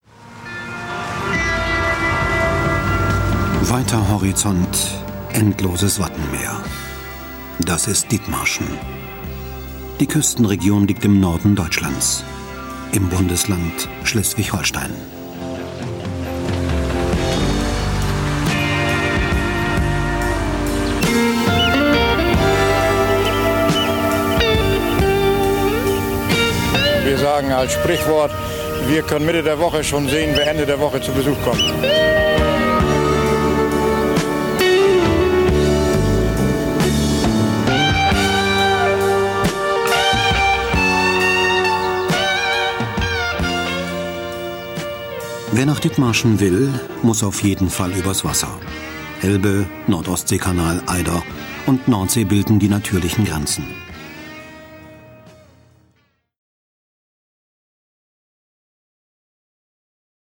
Profi-Sprecher deutsch.
Kein Dialekt
Sprechprobe: Werbung (Muttersprache):
german voice over artist